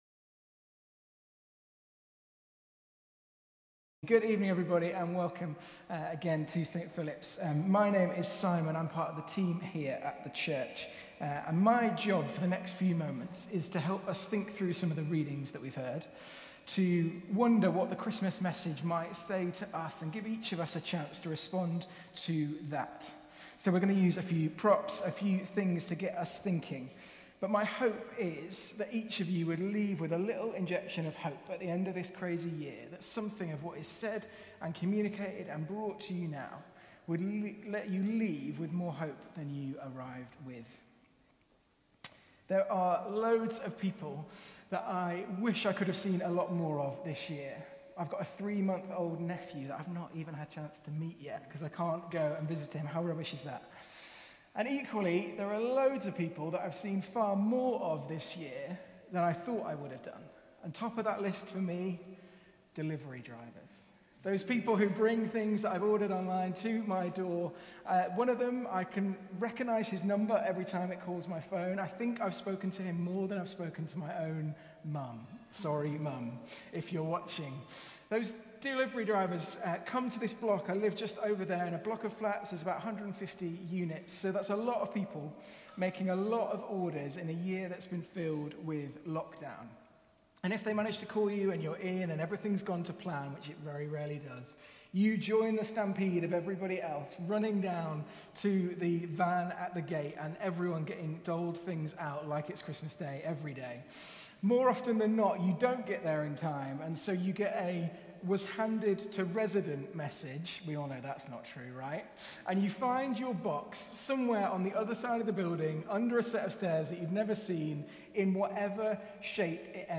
Carols by Candlelight - Sunday 13th December 2020